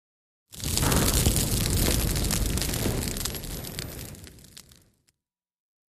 BrushFireCrackly PE700204
FIRE BRUSH FIRE: EXT: Bursts of roaring, crackly fire, amber burst in beginning.. Fire Burn.